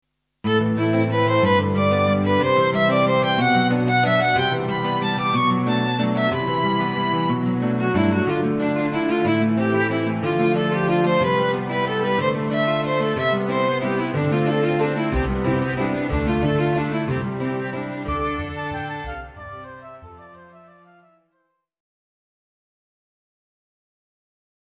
klassiek